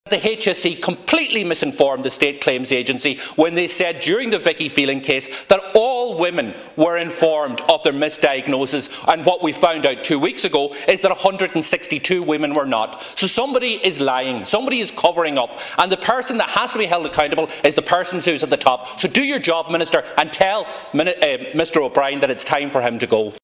Donegal Pearse Doherty told the Dail last night that Mr O’Brien must take responsibility………..